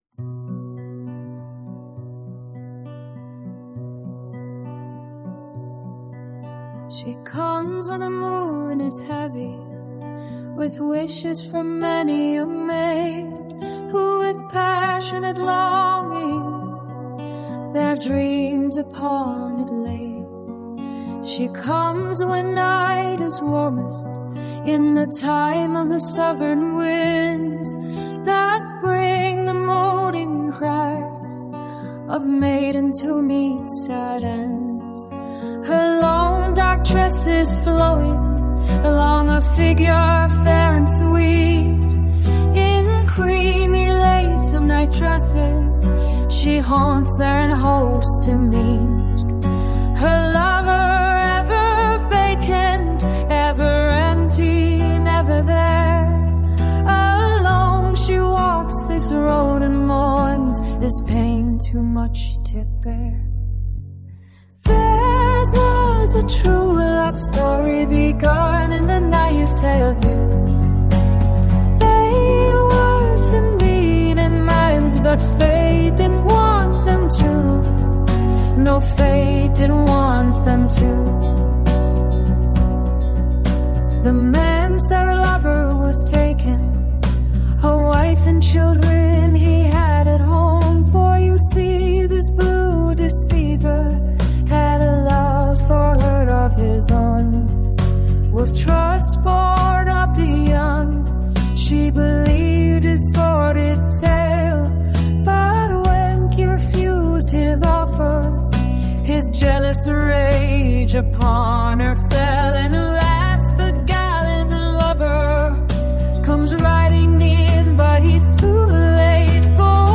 It's an app that can make musical demos of your song lyrics.